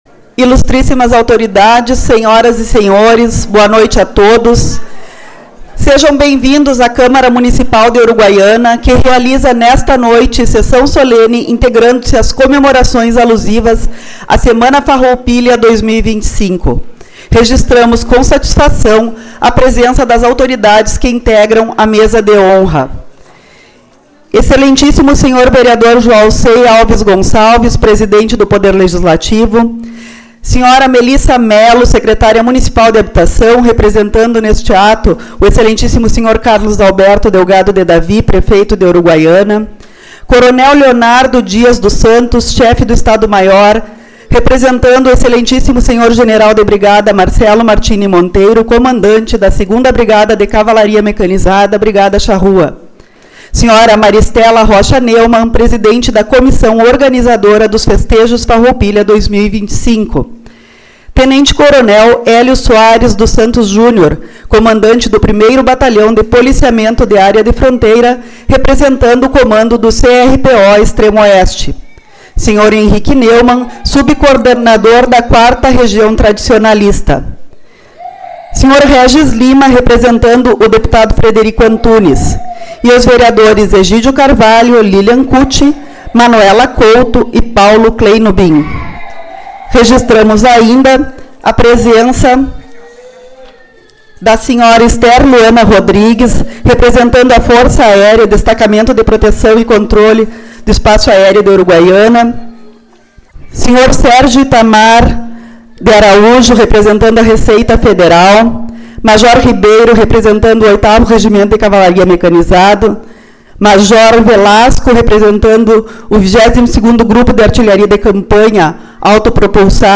18/09 - Sessão Solene-Semana Farroupilha